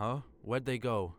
Combat Dialogue